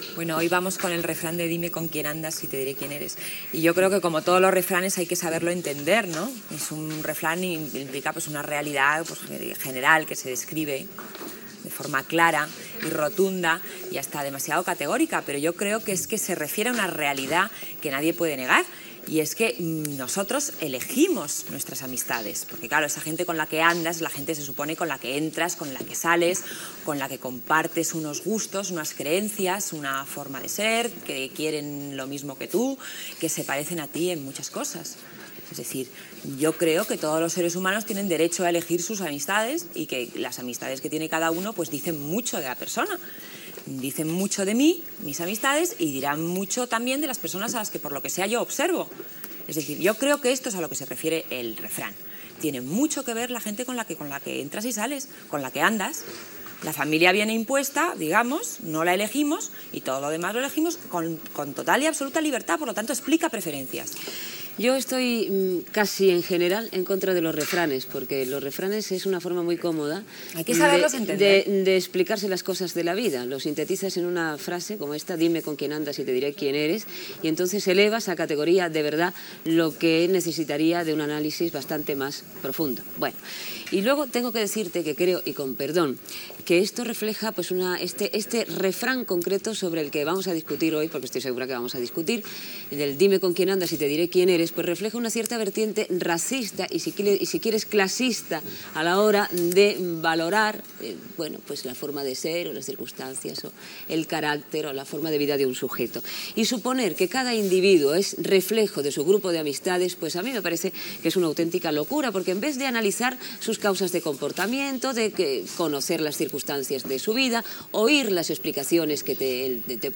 Debat sobre el refrany "Dime con quien andas y te diré quien eres"
Entreteniment
Audio extret del programa "Por la mañana" de TVE, publicat al web RTVE Play.